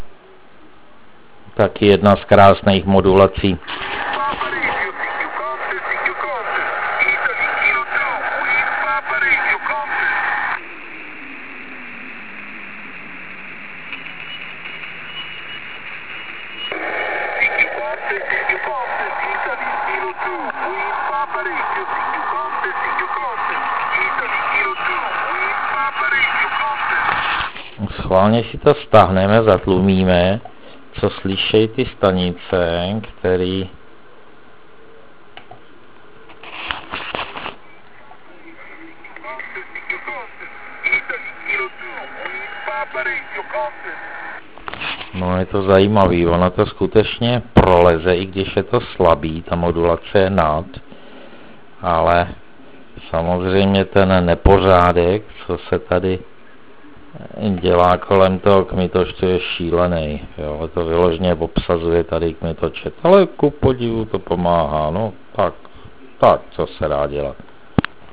tryskáč v pozadí